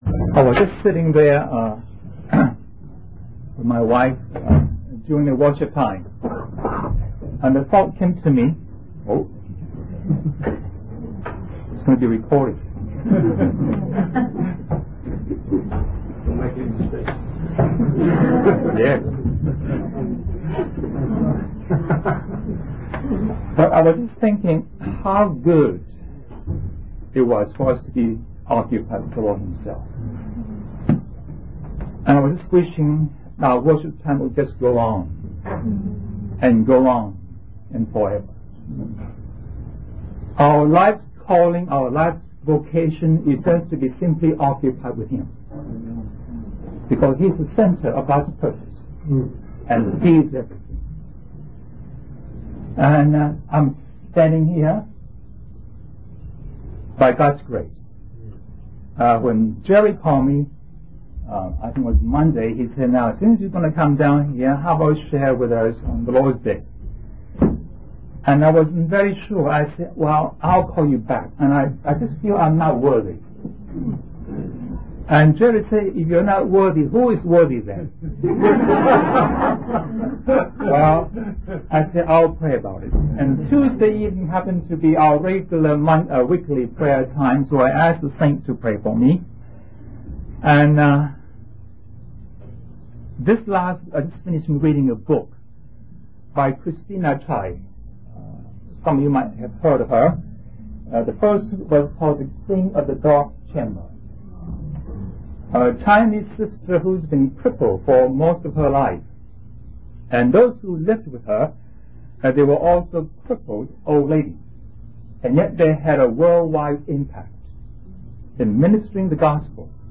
In this sermon, the speaker emphasizes the importance of dedicating oneself to God's work.